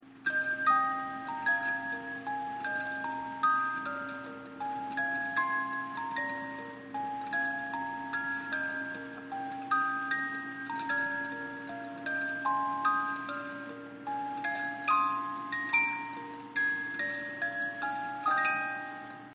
musique : Valse de l'Empereur - mi bemol majeur - durée 5 min 40 s -